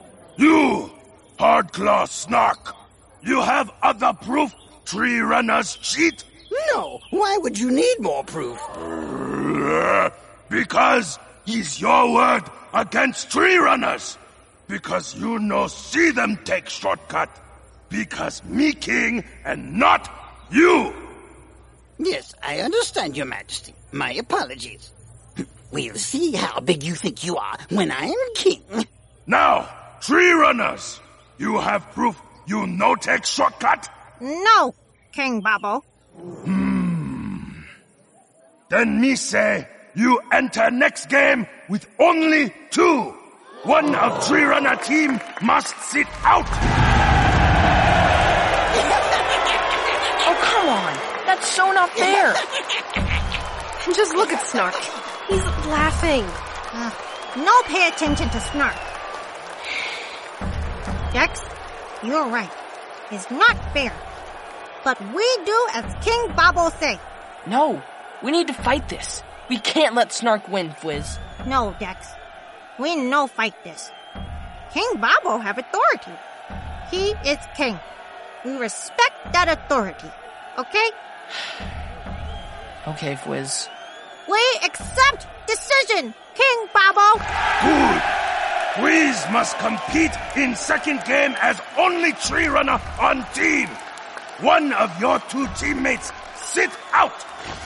Check out this clip to hear how Fwizz responded to the king in an unfair situation:
To help kids apply what they’re learning about influence, this 4-day reading plan will feature clips from The Gunga Games, an action-packed season from our eKidz family podcast, Into the Portal!